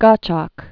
(gŏchôk, gŏtshôk), Louis Moreau 1829-1869.